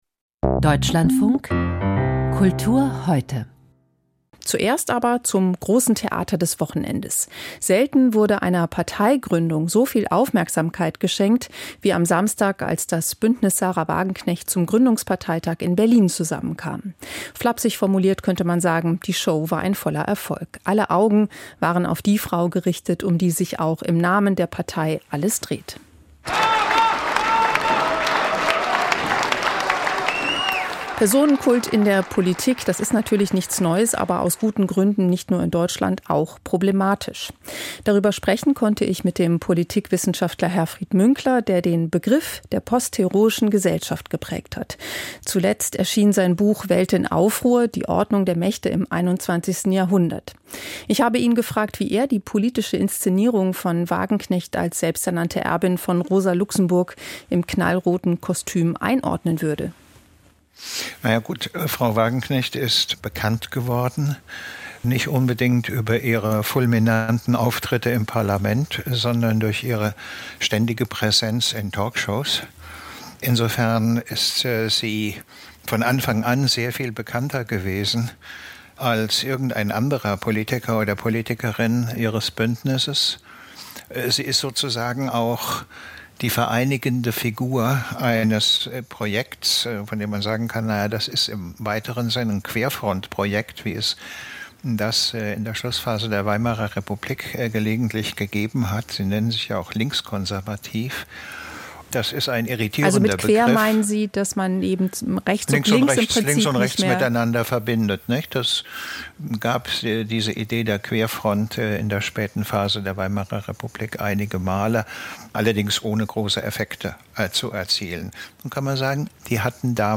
Personenkult und Demokratie. Politikwissenschaftler Herfried Münkler im Gespräch